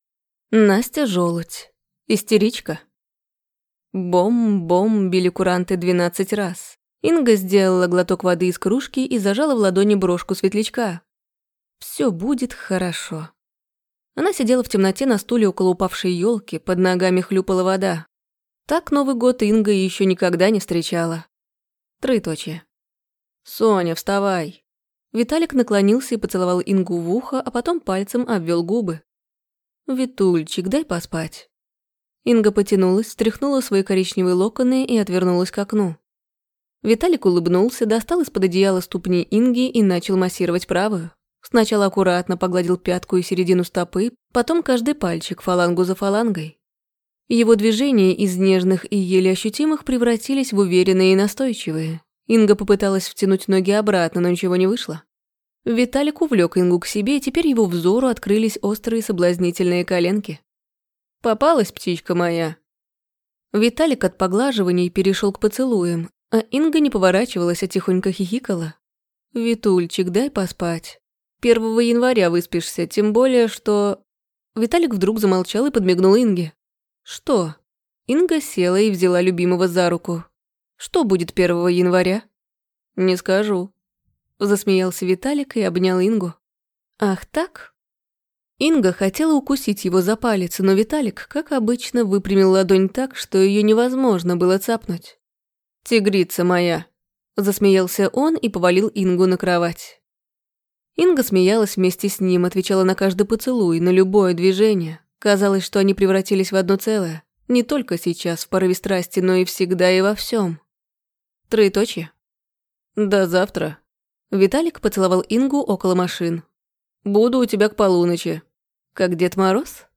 Аудиокнига Истеричка | Библиотека аудиокниг